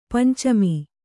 ♪ pancami